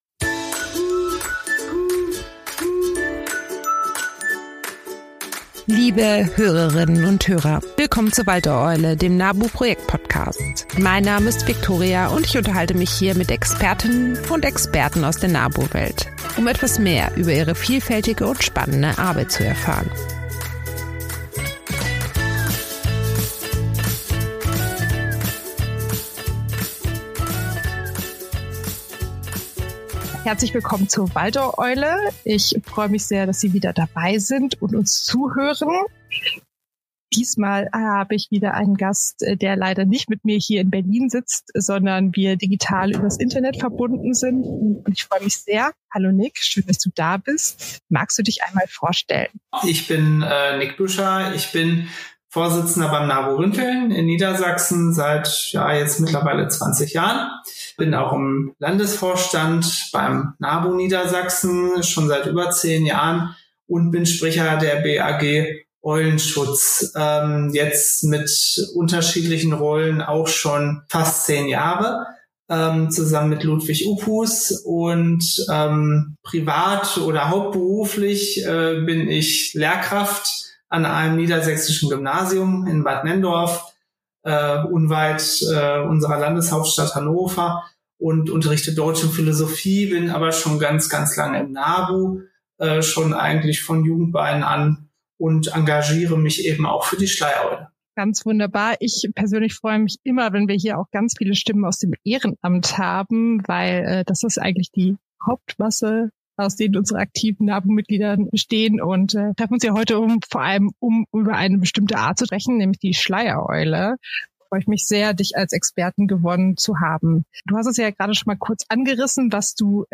Naturschutz aktiv gestalten - Ein Gespräch